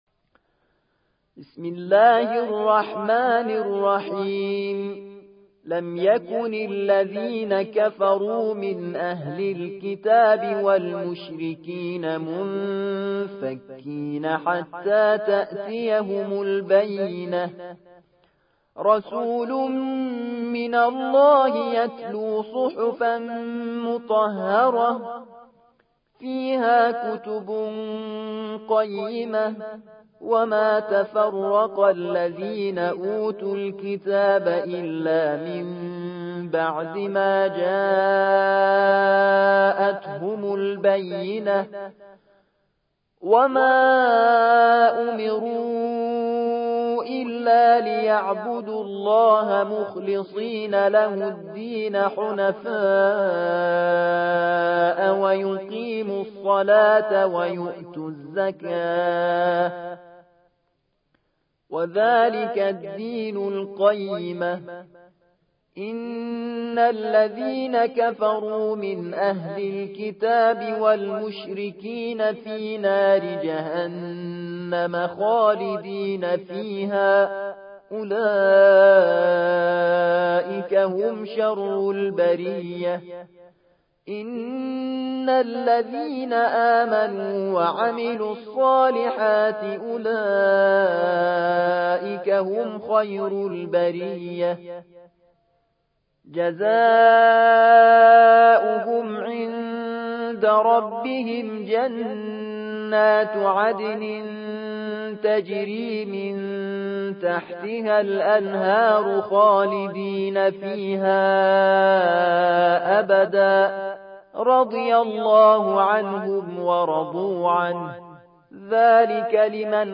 98. سورة البينة / القارئ